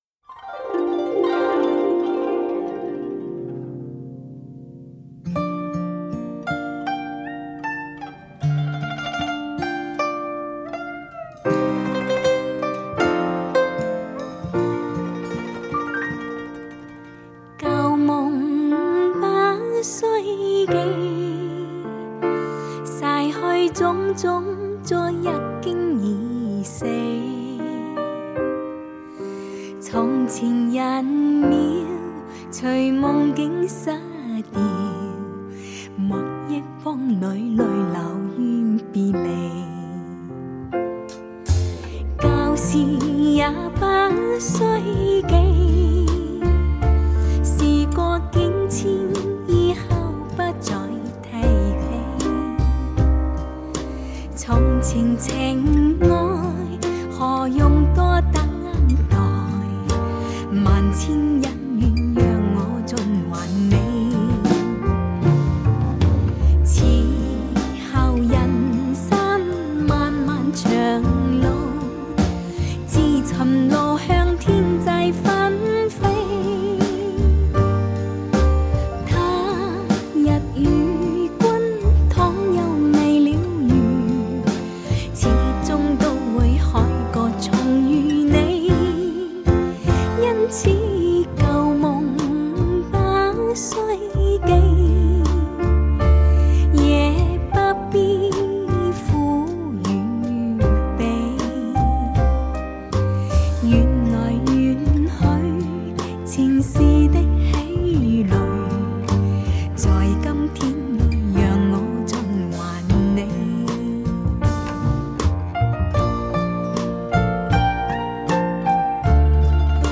温柔的，浪漫的，唯美的，心醉的，解读女人柔情款款的爱恋心声，歌婉于唱，盖声出莺吭燕舌间，性情万可寄予陶写。